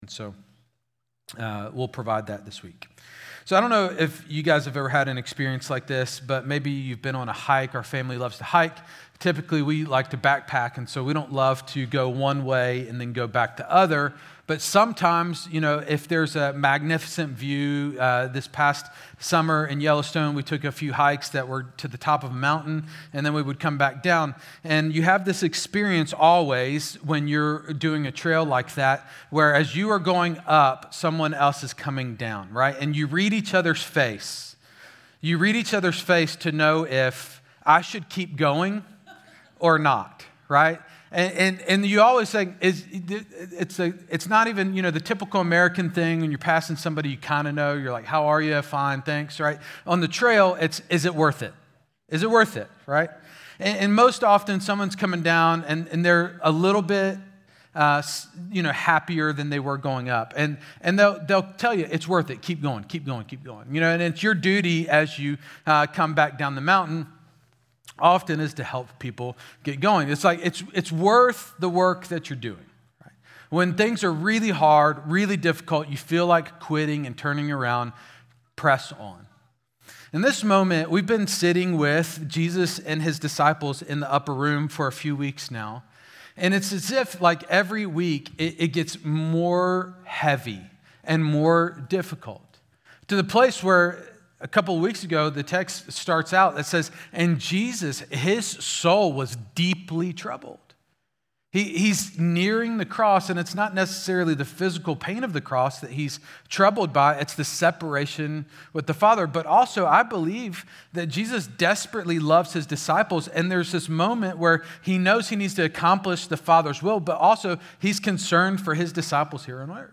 Exchange Church Sermons